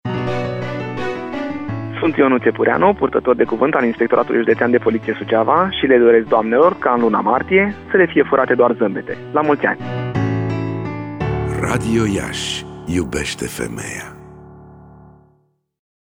De astăzi, timp de o săptămână, pe frecvenţele de 1053 Khz, 90,8, 94,5 şi 96,3 Mghz, vor fi difuzate următoarele spoturi: